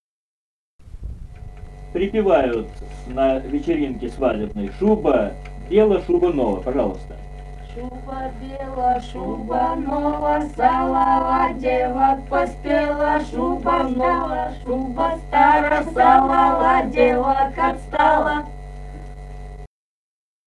Русские песни Алтайского Беловодья 2 [[Описание файла::«Шуба [нова], шуба [бела]», игровая на вечеринке у невесты.
Республика Казахстан, Восточно-Казахстанская обл., Катон-Карагайский р-н, с. Фыкалка, июль 1978.